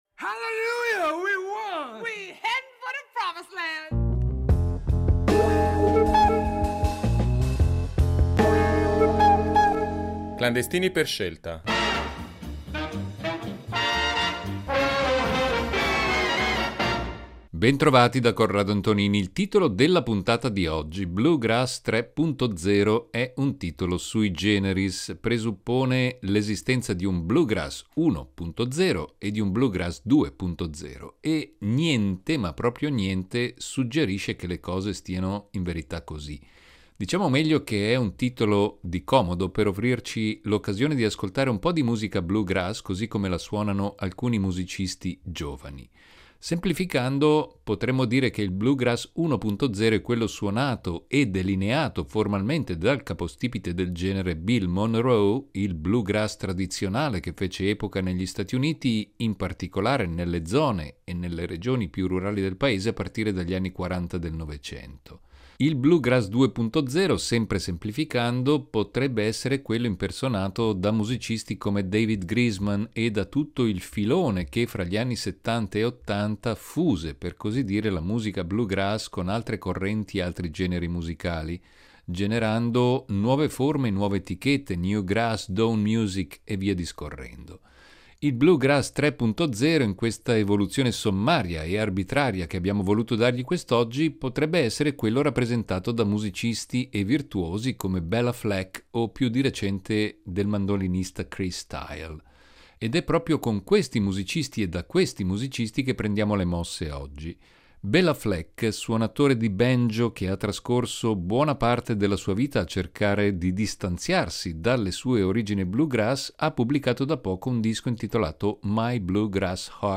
Bluegrass 3.0